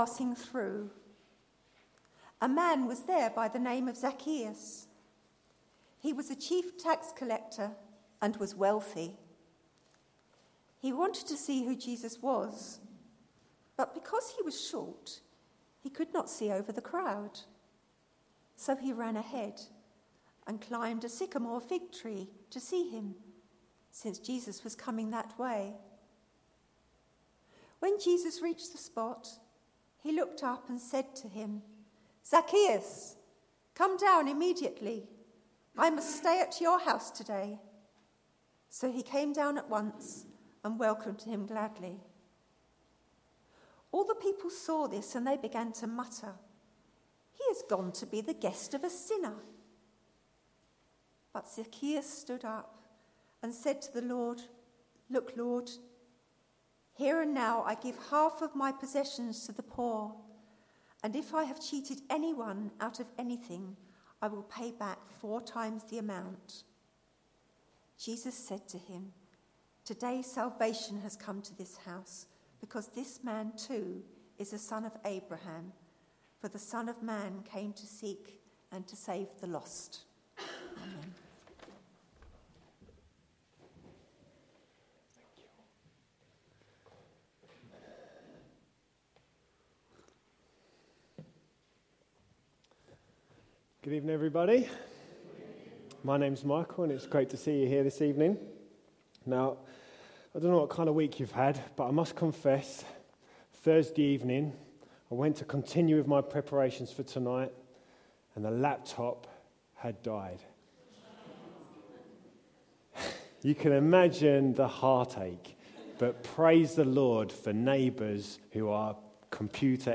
A message from the series "What I love about Jesus."